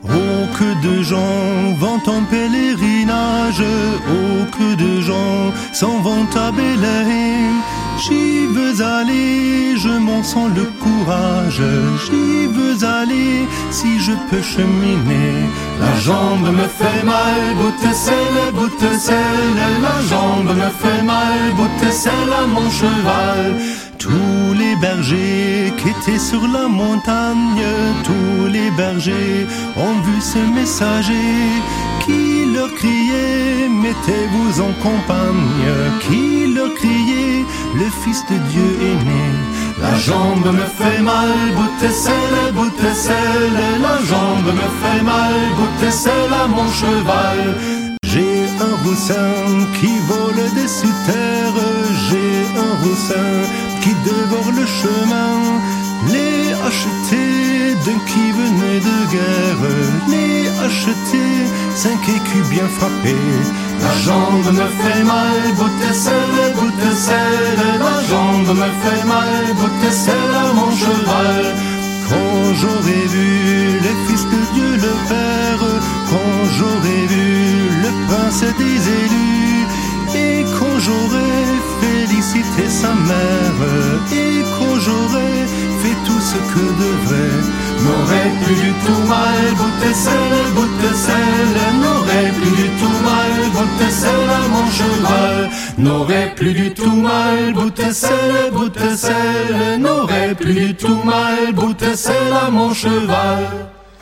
Übungsmusik aus einigen YouTube Videos herausgeschnitten (mittlere Qualität) - als mp3- oder wav-File
La jambe me fait mal (provencalisches Hirtenlied)  Download this file (La_Jambe_me_fait_mal_G.pdf)